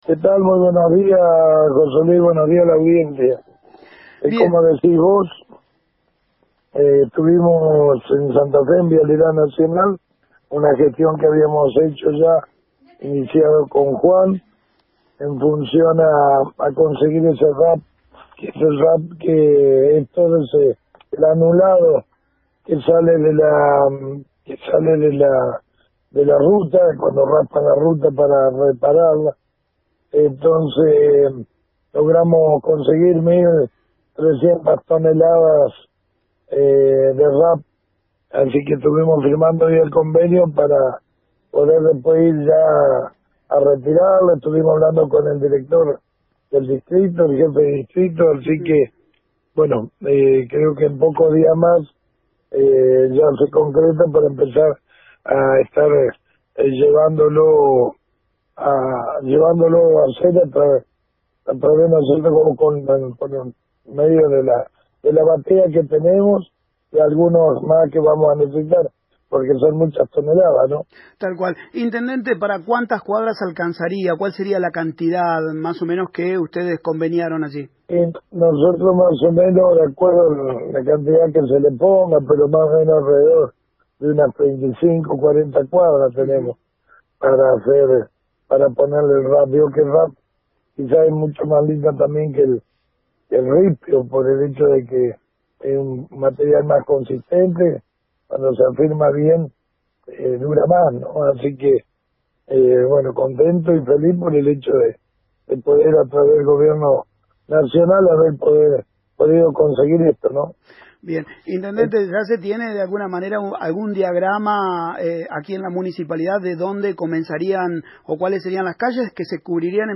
En contacto telefónico con Radio EME desde la ciudad de Santa Fe, el intendente Camilo Busquets confirmó la firmó convenio con Vialidad Nacional, por un total de 1300 toneladas de Rap Asfáltico que será utilizado para mejorar las distintas calles de la ciudad.